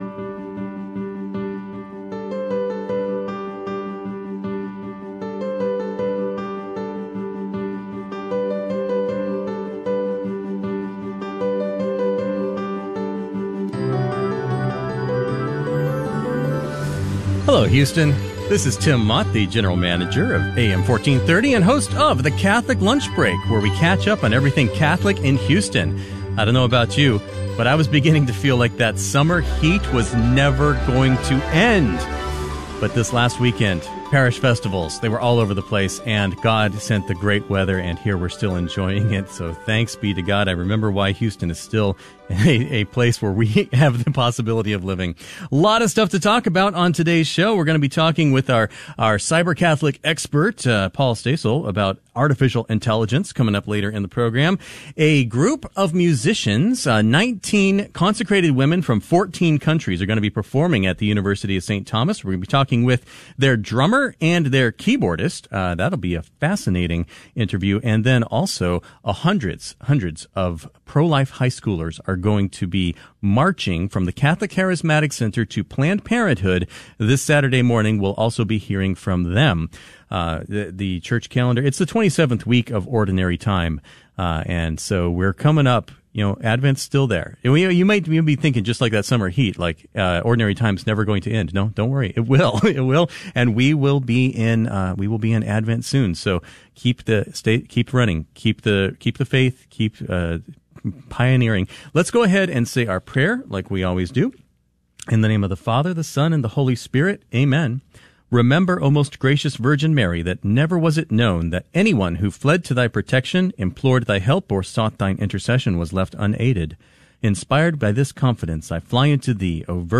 It airs live every Monday at noon in Houston on AM 1430 KSHJ, and podcasts here for your listening convenience.